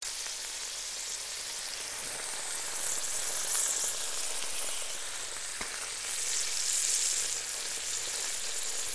Звуки грибов
жарим грибы на сковороде со звуком